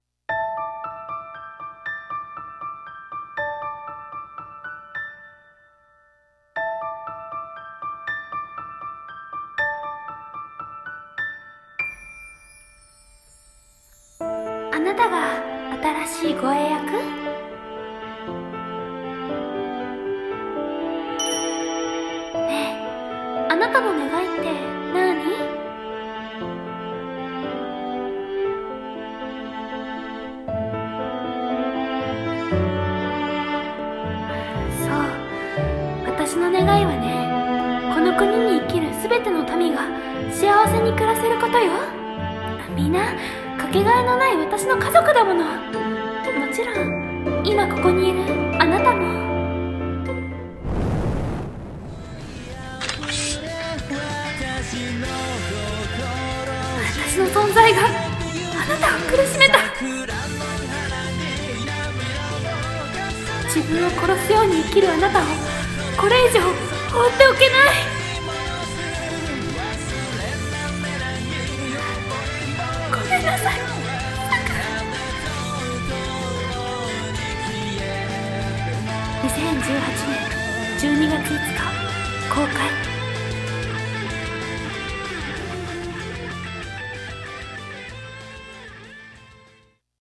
【CM風声劇台本】永世有明心中【2人用】